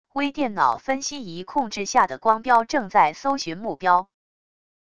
微电脑分析仪控制下的光标正在搜寻目标wav音频